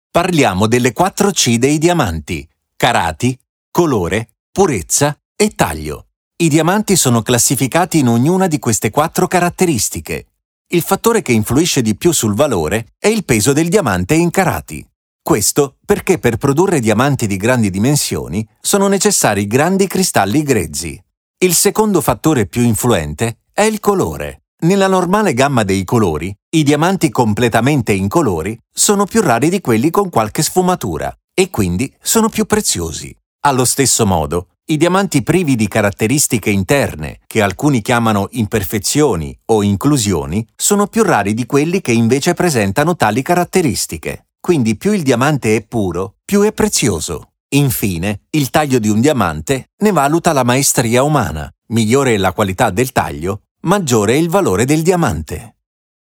E-learning
My recording room features state-of-the-art acoustic treatment, ensuring crystal-clear and broadcast-quality audio.